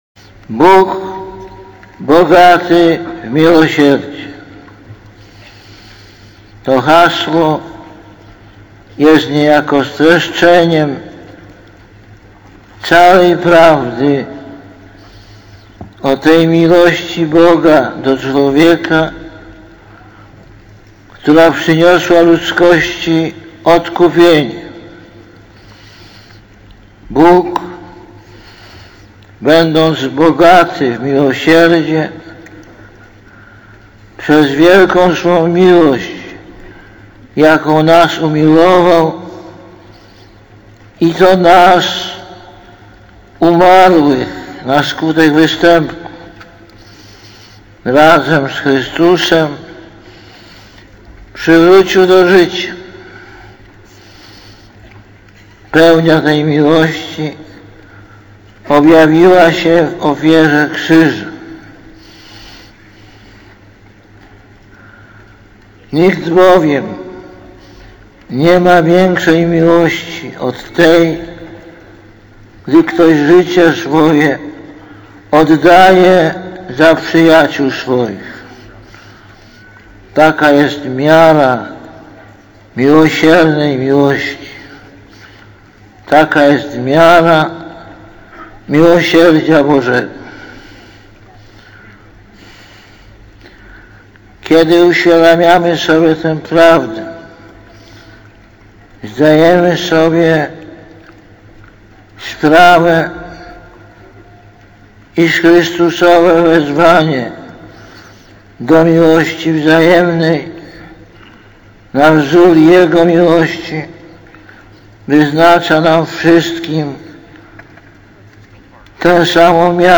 Lektor: Z homilii podczas Mszy św. beatyfikacyjnej (Kraków, 18 czerwca 2002,